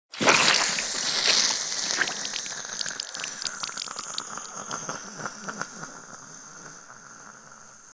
Acid_Near_01.ogg